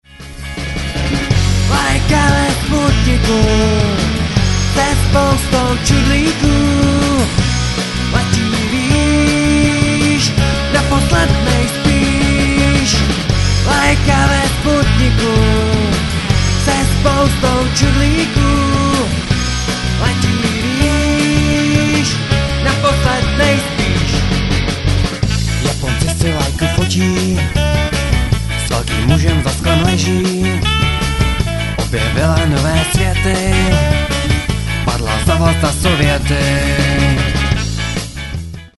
Nahráno na jaře 2005 v Říčanech.